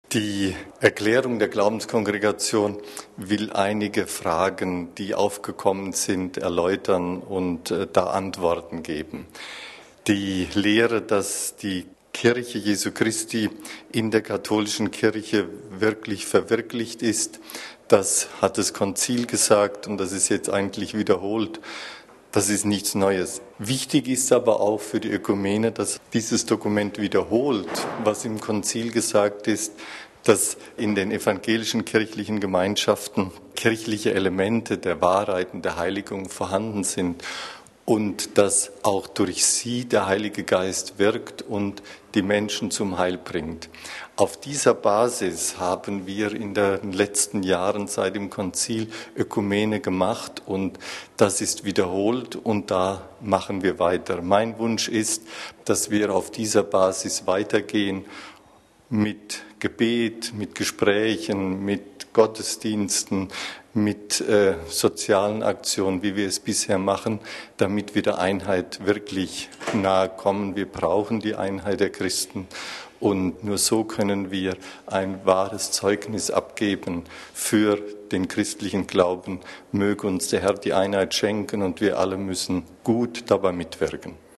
Erzbischof Dr. Ludwig Schick zu „Ecclesia subsistit in Ecclesia catholica“: